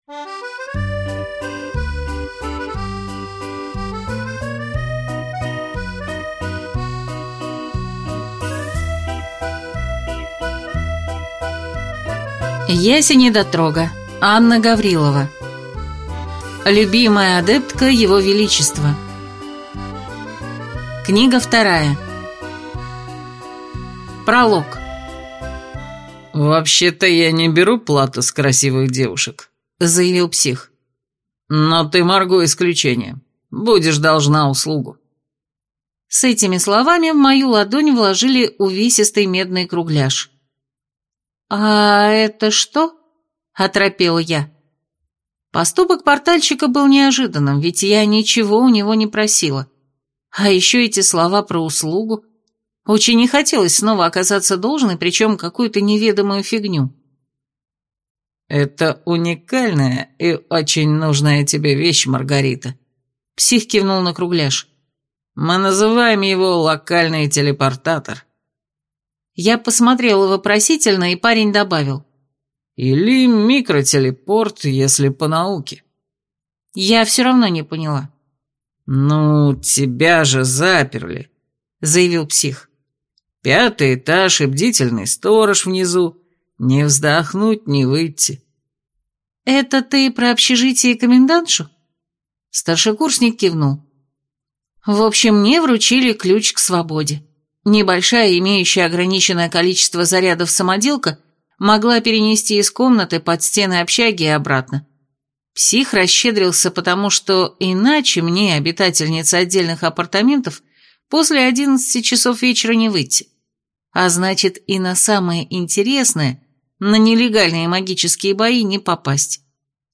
Аудиокнига Любимая адептка его величества. Книга 2 | Библиотека аудиокниг